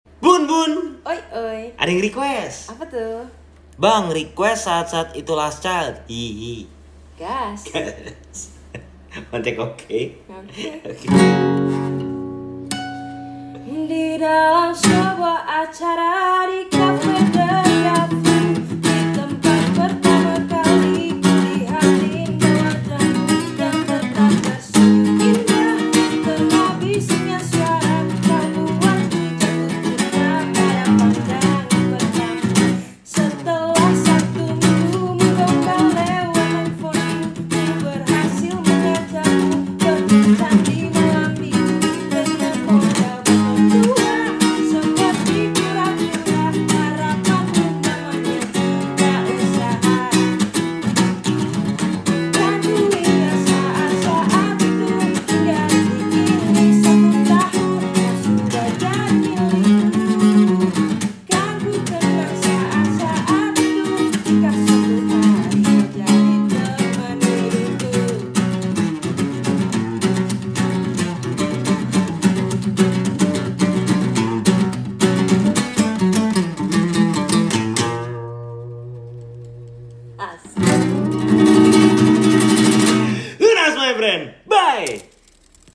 Versi Pop Punk Gitar Nylon.